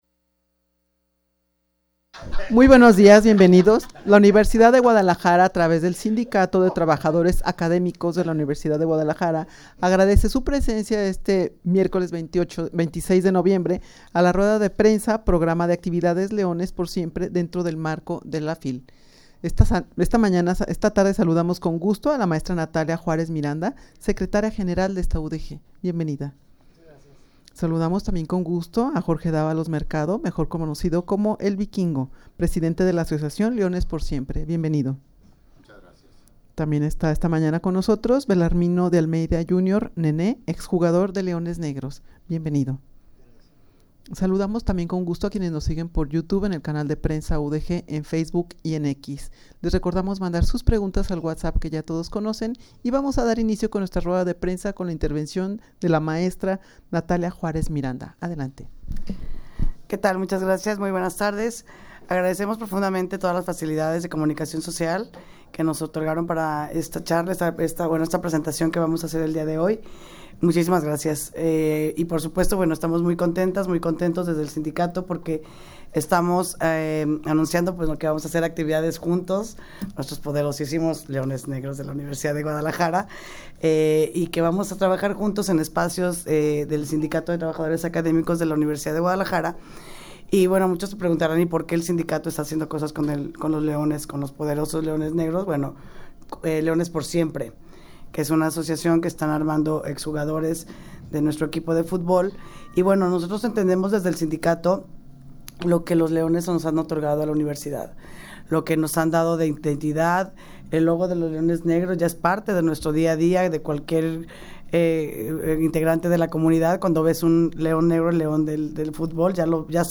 Audio de la Rueda de Prensa
rueda-de-prensa-programa-de-actividades-leones-por-siempre-dentro-del-marco-de-la-fil.mp3